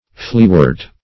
Fleawort \Flea"wort`\, n. (Bot.)